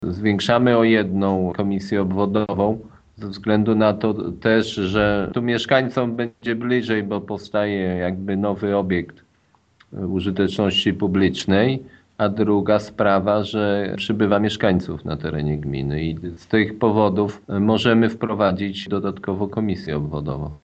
- W wyborach, które są planowane na 2014 rok, mieszkańcy Dominowa i Wólki Abramowickiej, którzy do tej pory oddawali głosy w szkole w Mętowie, teraz będą głosować w nowej komisji obwodowej, utworzonej w nowo budowanym urzędzie w Dominowie - informuje wójt Jacek Anasiewicz: